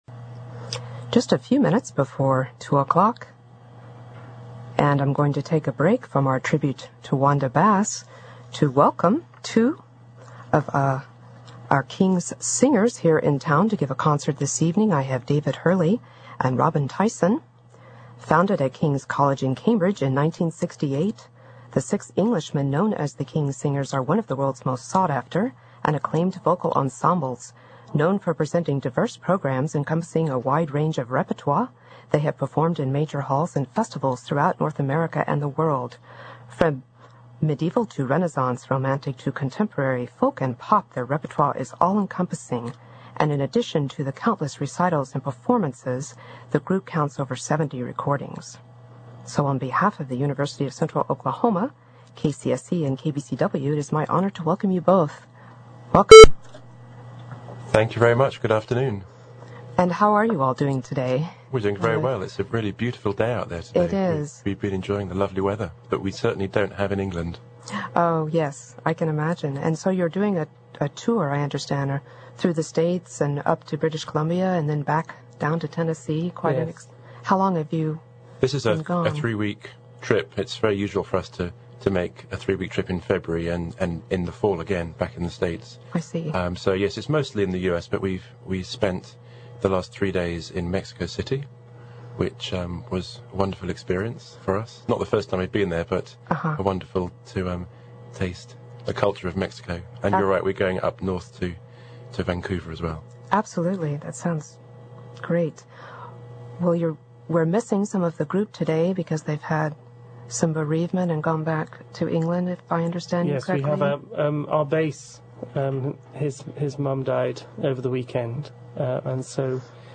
Studio Appearance